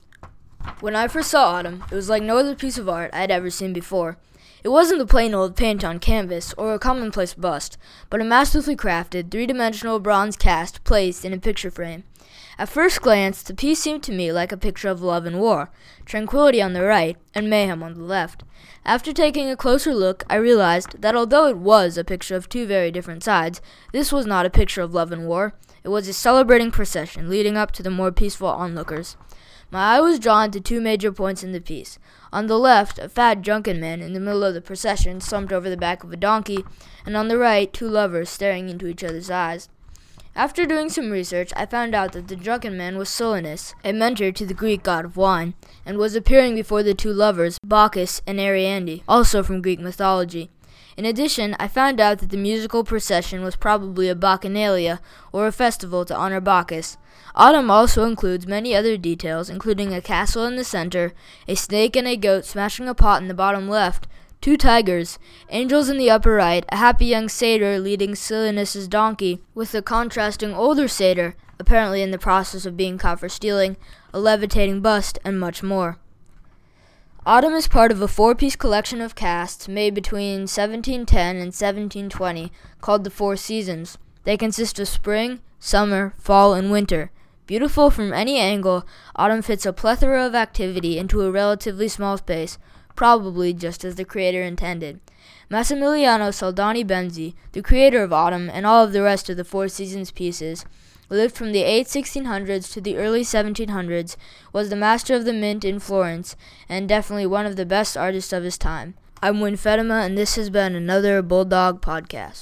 Audio Tour – Bulldog Podcast